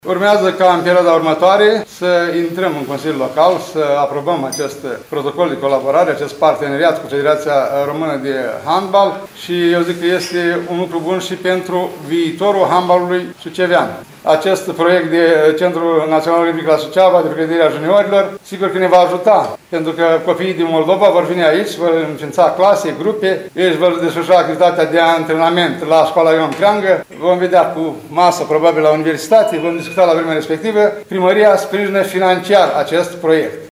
Primarul ION LUNGU a precizat că viitorul centru olimpic va putea beneficia de sala olimpică programată a se construi la ieșirea din Suceava spre Fălticeni.